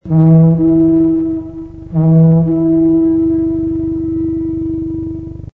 AKAI GX 636 umgebaut für Magnettonwiedergabe
Aus unserem Filmfundus haben wir eine 120 Meter Super8 Filmspule mit Stereoton von Weltreiseaufnahmen aus den 70er Jahren herausgesucht und 12 kurze Samples zum kurzen Anspielen für Sie angefertigt: